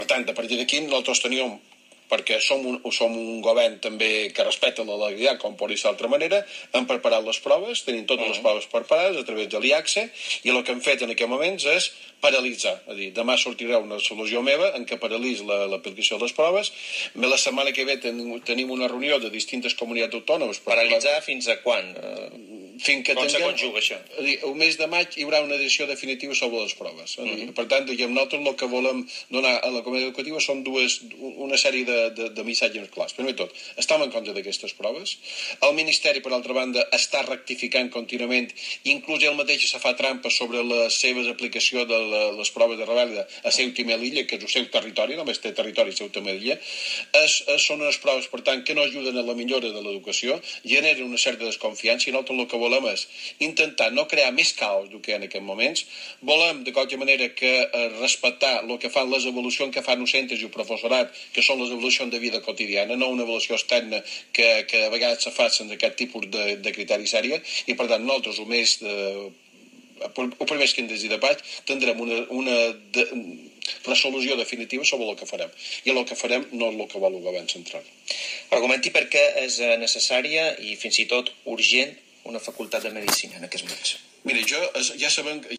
En data d’avui, al programa “Al Dia” d’IB3 Ràdio, el Conseller d’Educació ha dit que demà es farà pública una resolució que PARALITZA TOTES LES PROVES (3er i 6è).
Aquí teniu el tall de veu del CONSELLER.